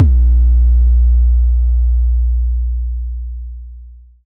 808 Kick 25_DN.wav